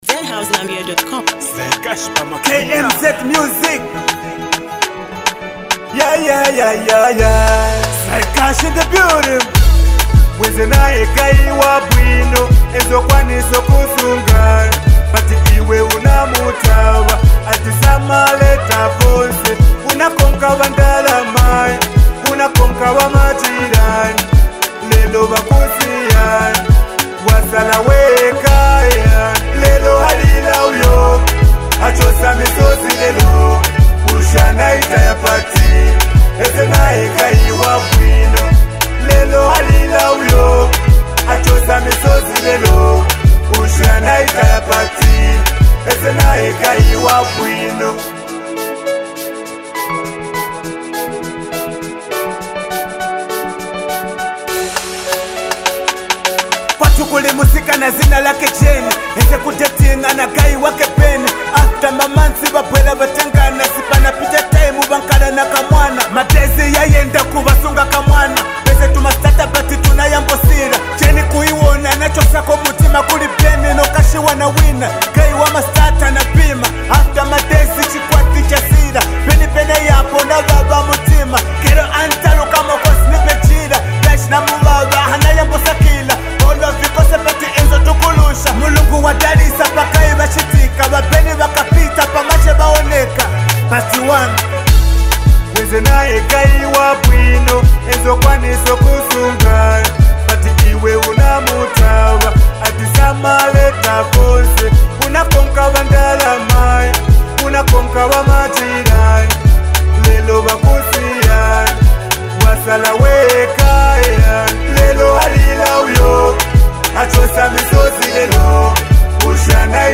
clean production